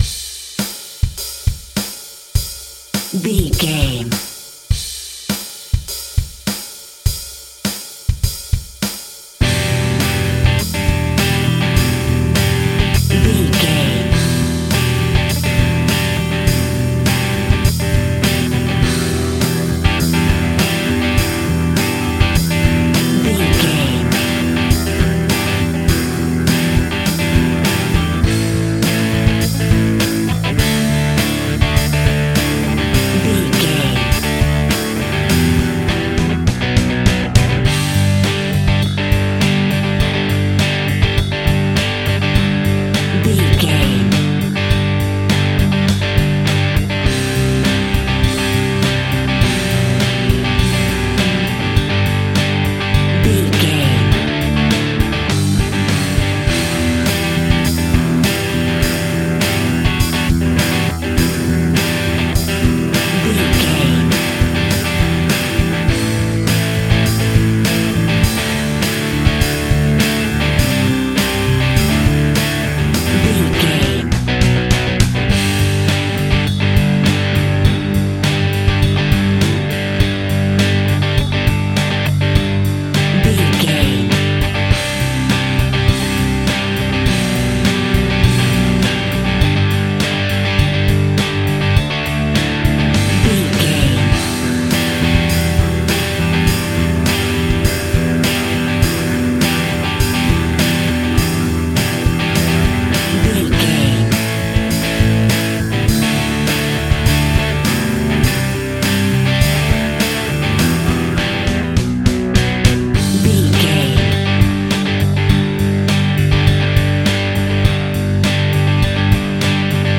Uplifting
Ionian/Major
heavy rock
blues rock
distortion
hard rock
Instrumental rock
drums
bass guitar
electric guitar
piano
hammond organ